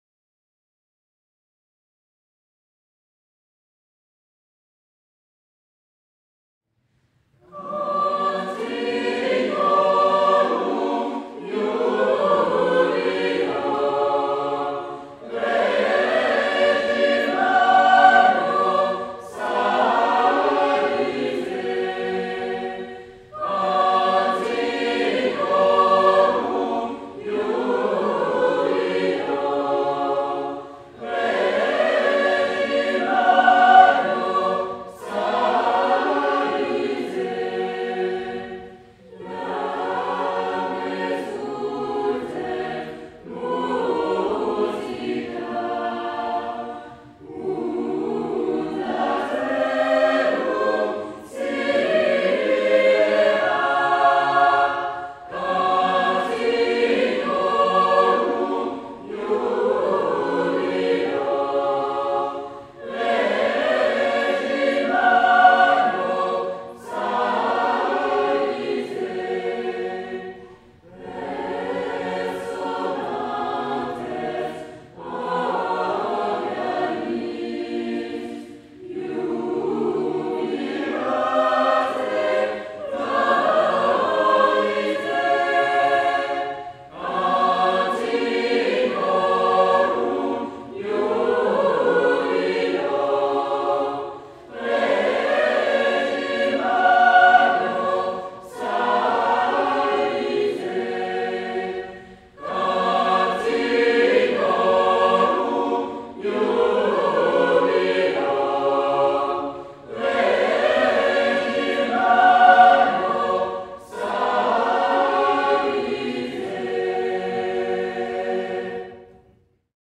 - Œuvre pour chœur à 4 voix mixtes (SATB)